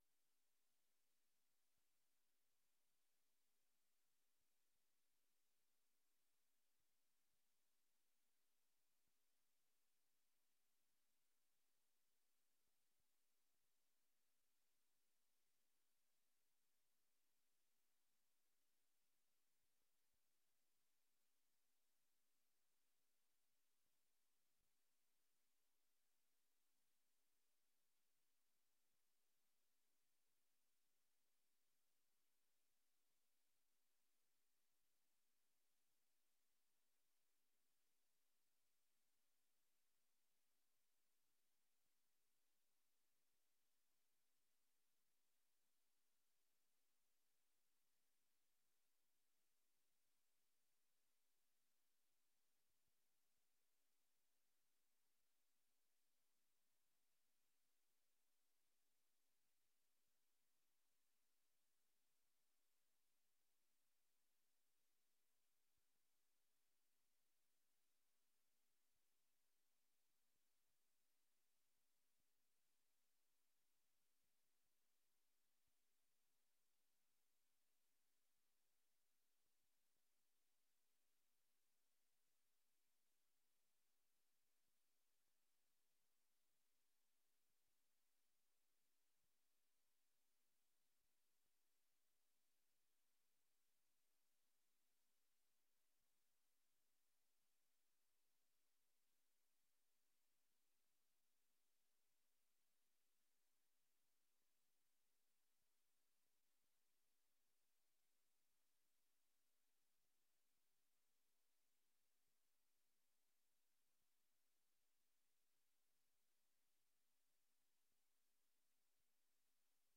Raadsvergadering 17 december 2024 19:30:00, Gemeente Dronten